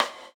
Index of /90_sSampleCDs/USB Soundscan vol.10 - Drums Acoustic [AKAI] 1CD/Partition C/03-GATEKIT 3